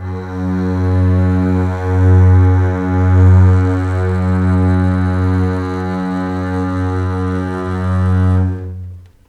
F#2 LEG MF R.wav